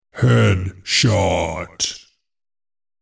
Вы найдете разные варианты ударов – резкие, глухие, с эхом – для использования в монтаже, играх или других творческих задачах.